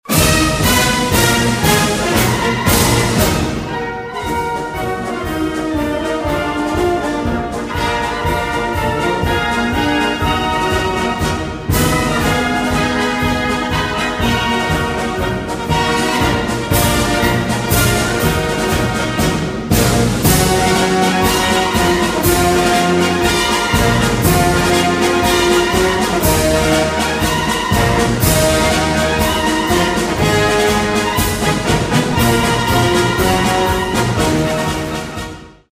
без слов , марш
инструментал